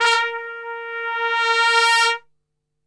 A#2 TRPSWL.wav